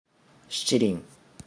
Ja-Shichirin.oga.mp3